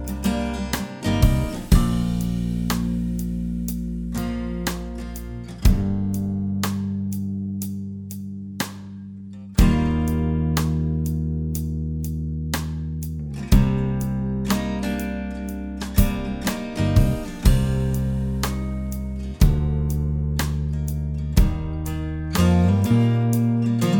Explicit Rock 5:14 Buy £1.50